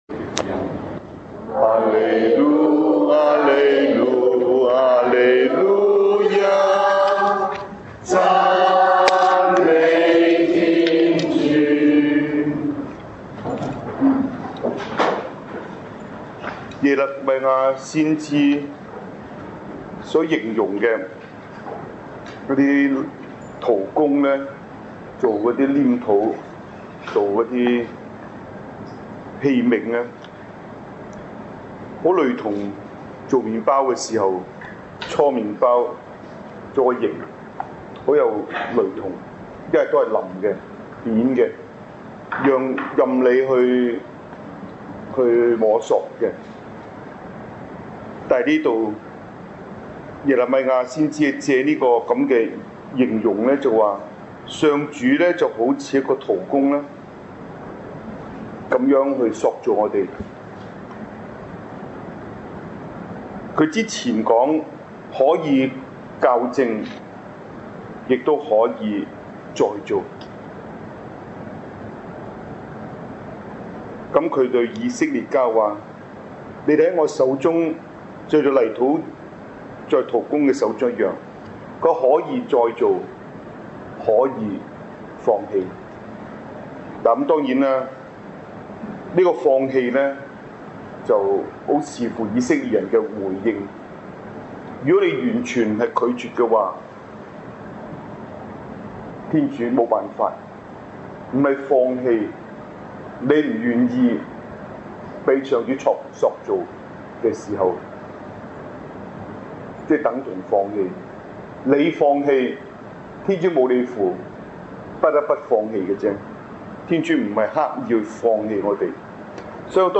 神父講道 2012年8月